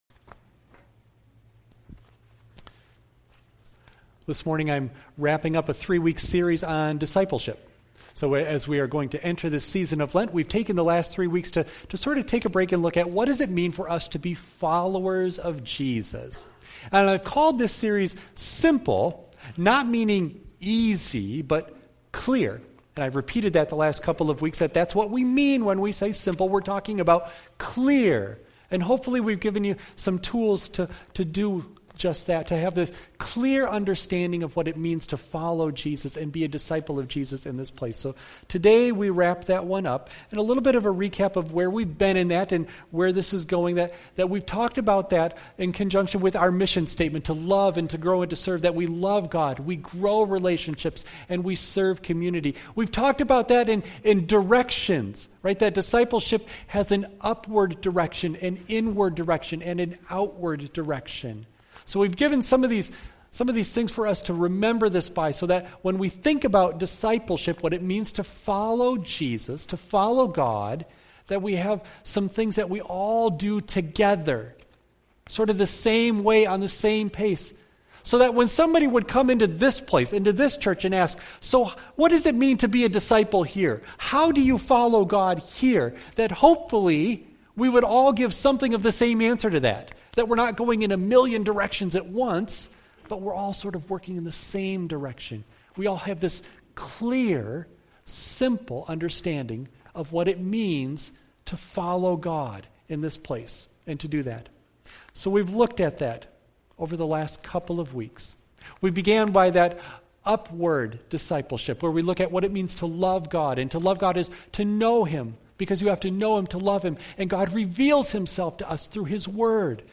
Matthew 25:31-46 Service Type: Sunday AM Bible Text